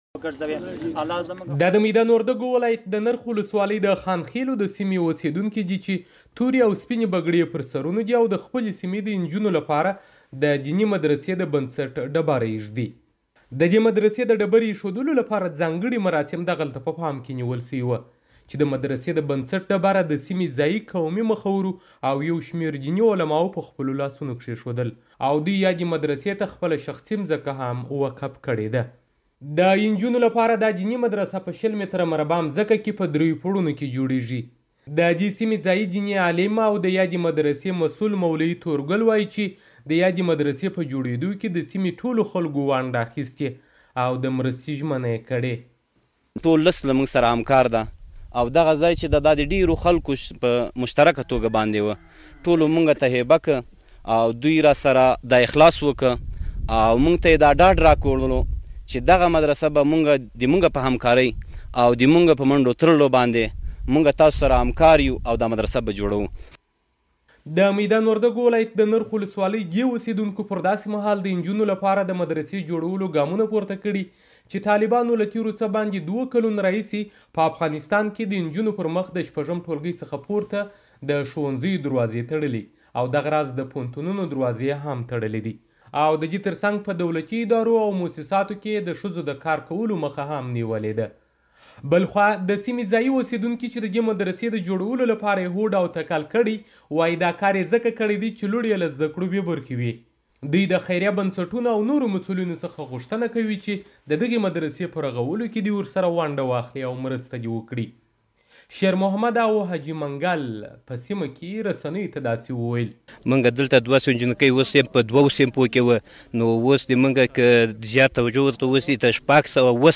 د مدرسې راپور